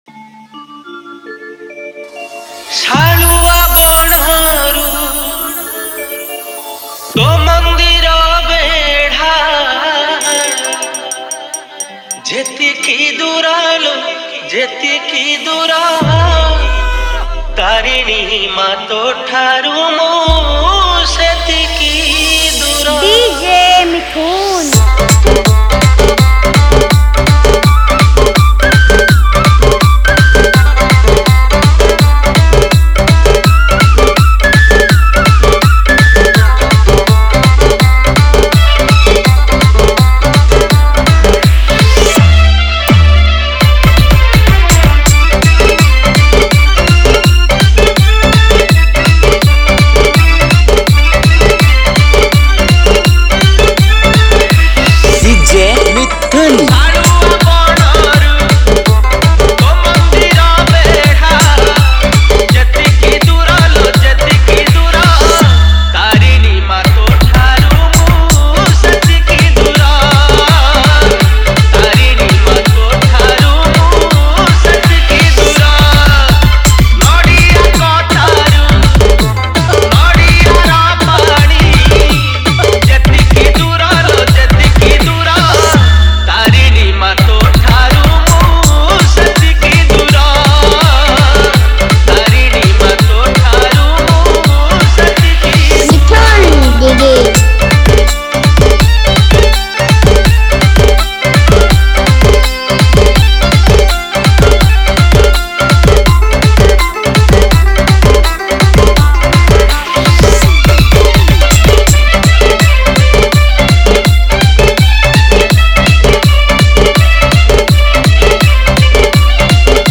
• Category:Odia Bhajan Dj Song 2019